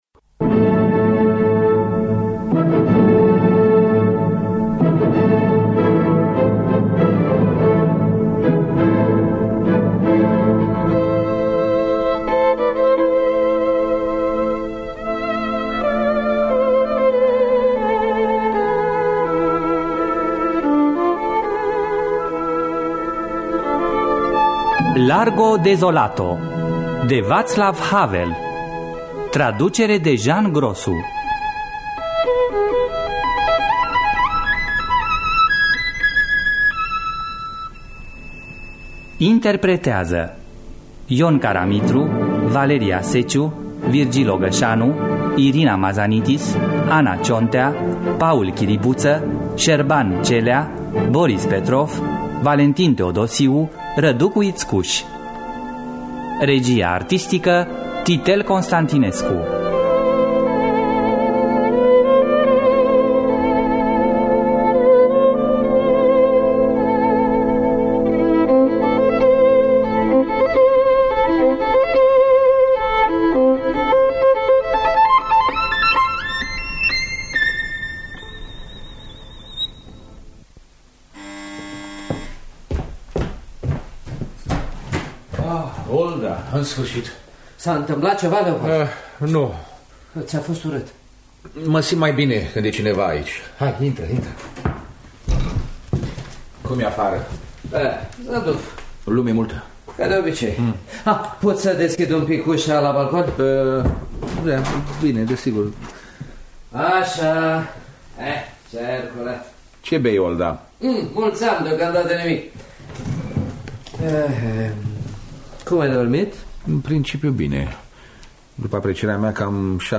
Largo Desolato de Václav Havel – Teatru Radiofonic Online